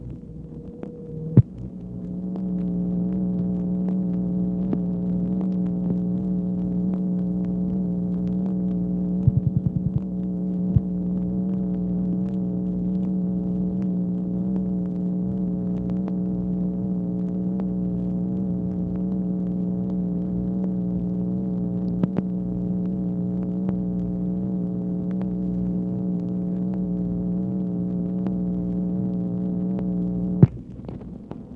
MACHINE NOISE, May 20, 1965
Secret White House Tapes | Lyndon B. Johnson Presidency